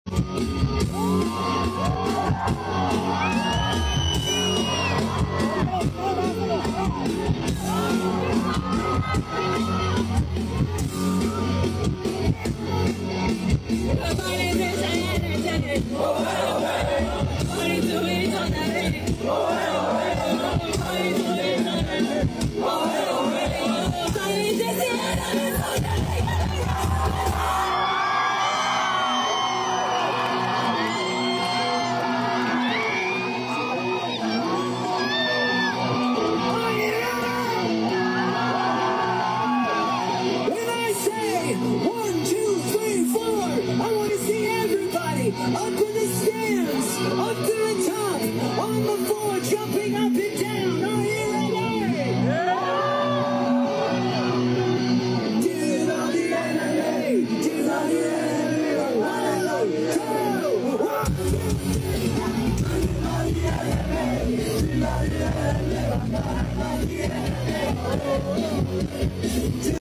sube una fan al escenario para cantar
en su concierto de ayer